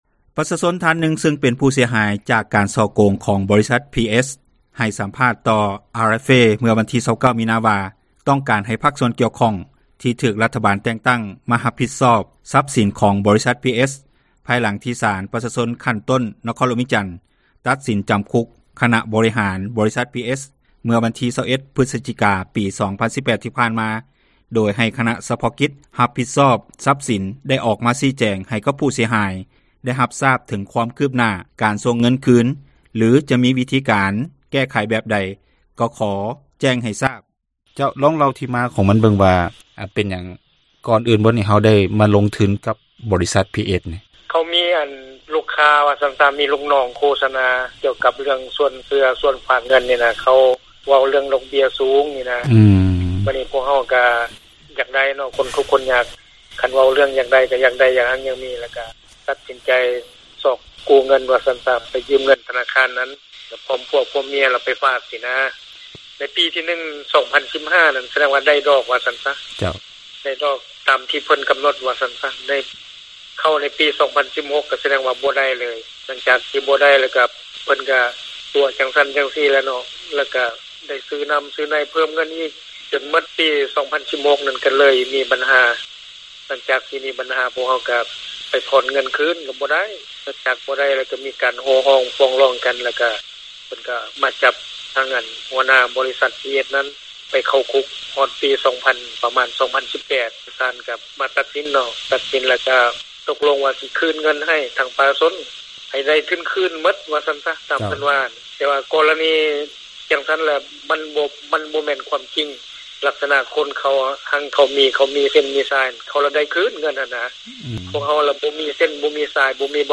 ສັມພາດປະຊາຊົນ ຜູ້ເສັຽຫາຍ: